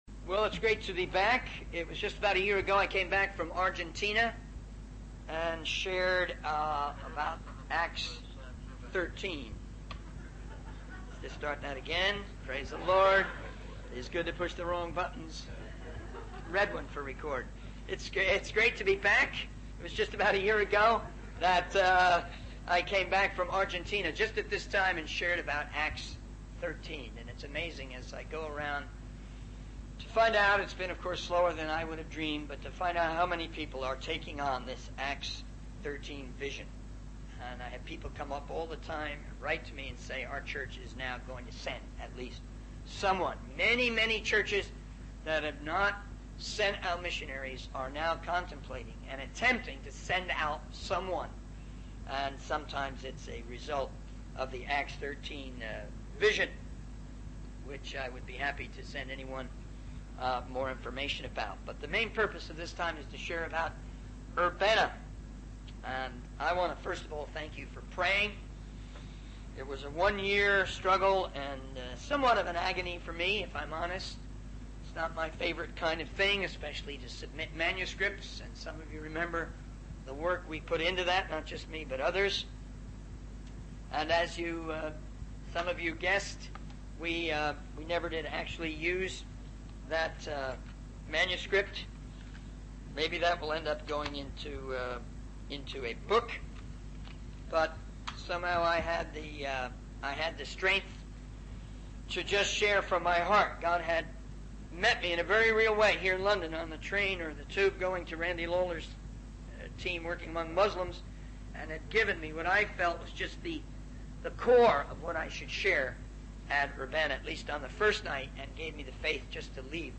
The video mentioned in the sermon transcript is titled 'Lordship of Christ' and was recorded at Urbana 87.